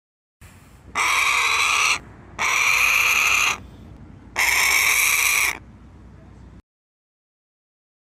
Harris Hawk | Parabuteo unicinctus
harrisHawk.mp3